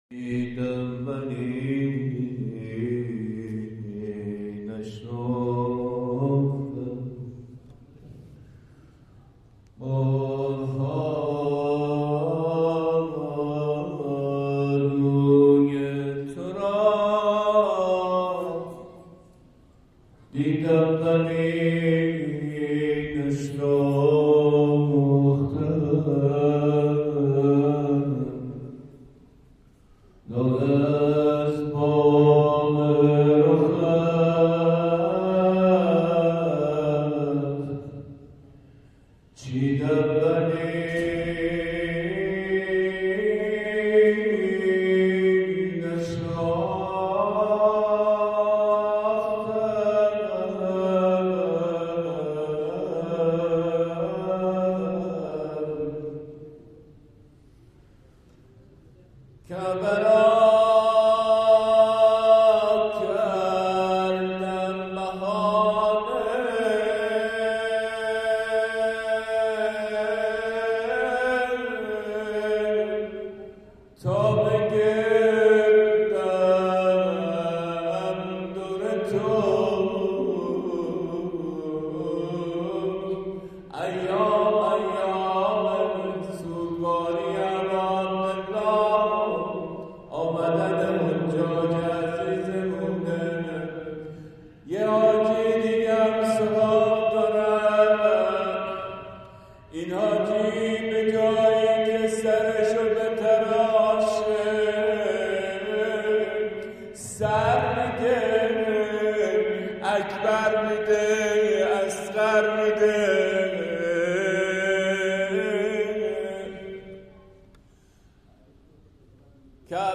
شب اول محرم
ذاکر و شاعر اهل بیت علیهم السلام